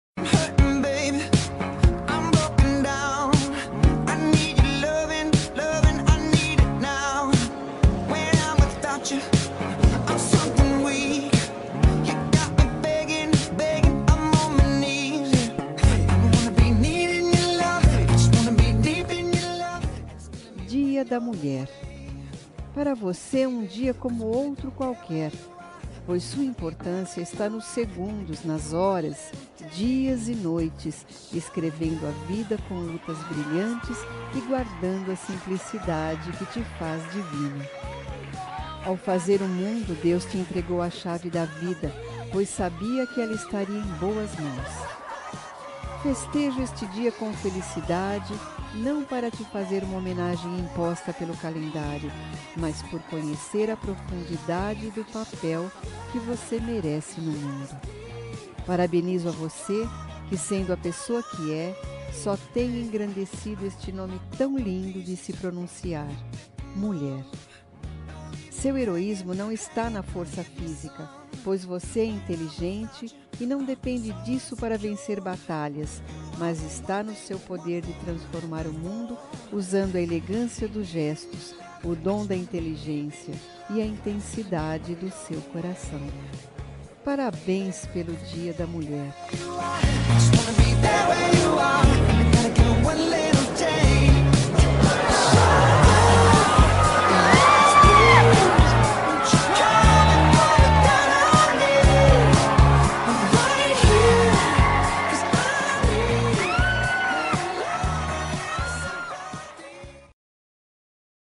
Dia das Mulheres Neutra – Voz Feminina – Cód: 5296